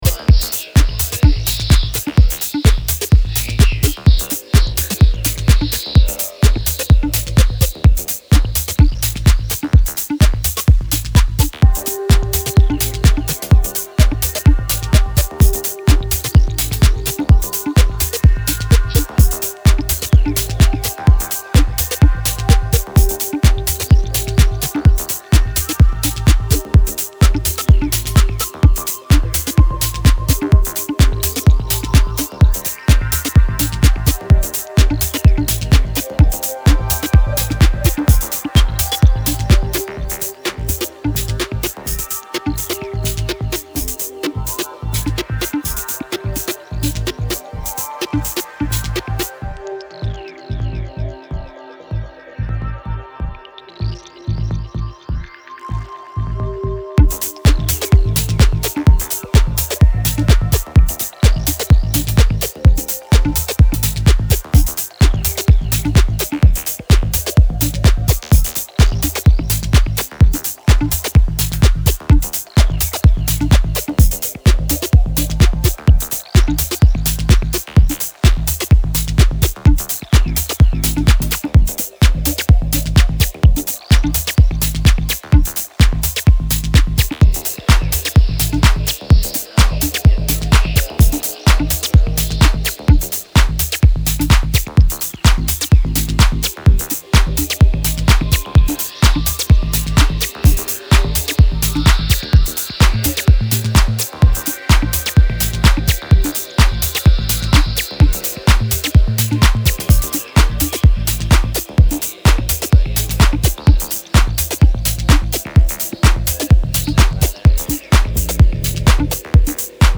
怪しく揺らぐ控えめなボトムと泡立つSEが展開するミステリアスなミニマル・ハウスです。